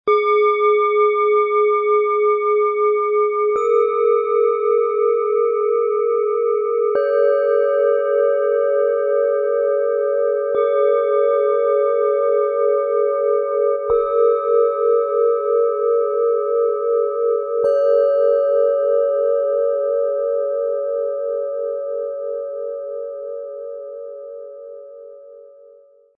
Fühle deine Seele, vertraue deiner Intuition und finde Klarheit - Set aus 3 Planetenschalen, Ø 10,8 -12,3 cm, 1,16 kg
Tiefer Ton – Neptun
Die Schwingung von Neptun bringt eine sanfte, fließende Energie, die hilft, sich von äußeren Reizen zu lösen.
Mittlerer Ton – Mond
Höchster Ton – Merkur
Diese handwerkliche Sorgfalt sorgt für reine, langanhaltende Klänge, die harmonisch miteinander verschmelzen.
Bengalen Schale, Schwarz-Gold, 12,3 cm Durchmesser, 6,6 cm Höhe